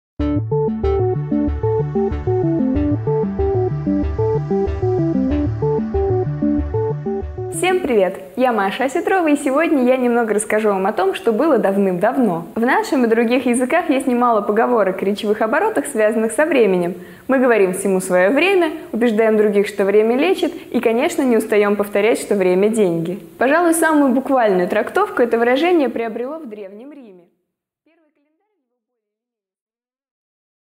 Аудиокнига 5 минут О календарях и динозаврах | Библиотека аудиокниг